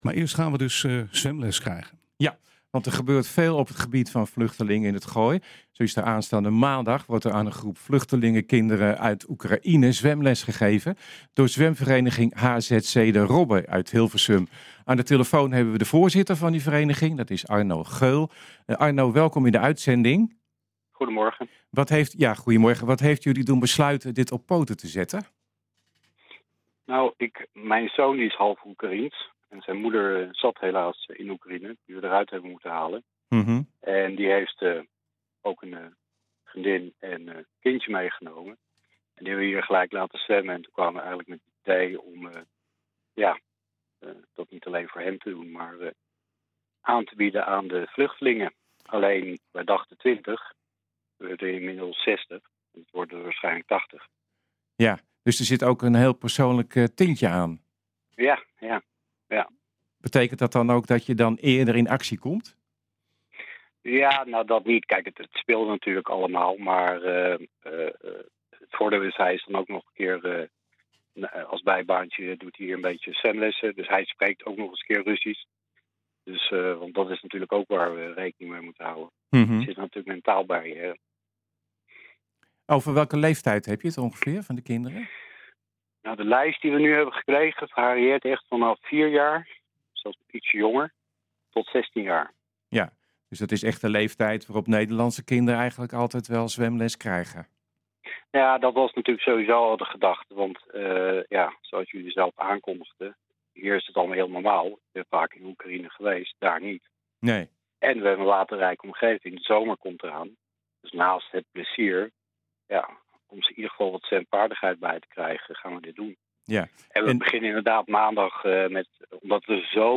Aanstaande maandag wordt aan een groep vluchtelingenkinderen uit Oekraïne zwemles gegeven door zwemvereniging HZC de Robben in Hilversum.